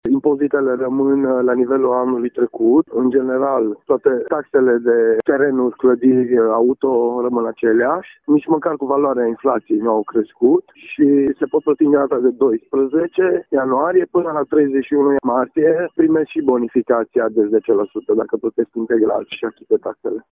Aceştia îşi pot achita în avans pentru tot anul impozitele și taxele. Valoarea dărilor a rămas la fel ca anul trecut, a precizat viceprimarul Municipiului Sighișoara, Dan Bândea: